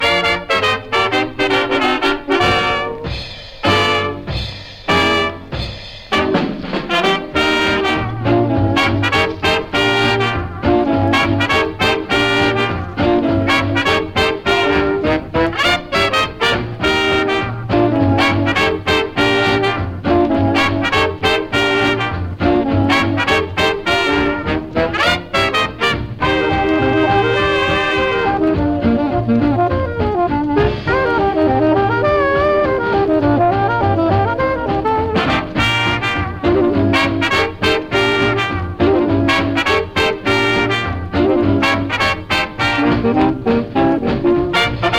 Jazz, Big Band　Netherlands　12inchレコード　33rpm　Mono